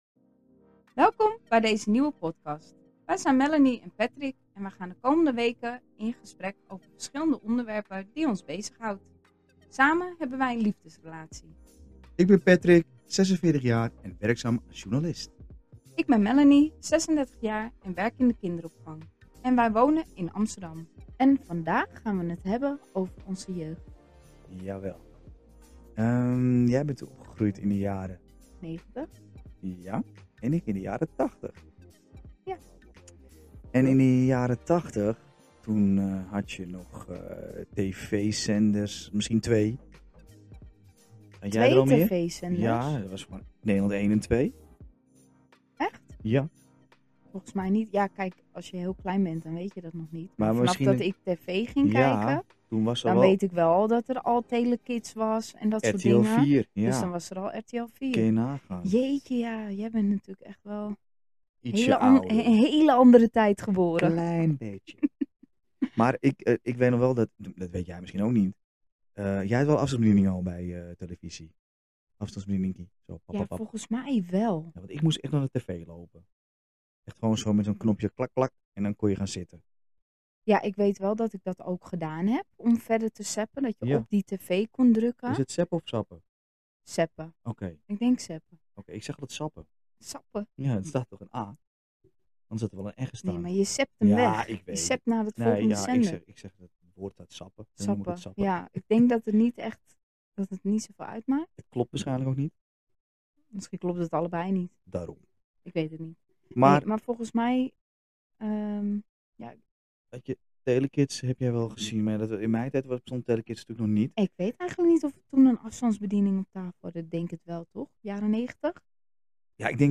Keukentafel-gesprekken van een liefdeskoppel over uiteenlopende onderwerpen.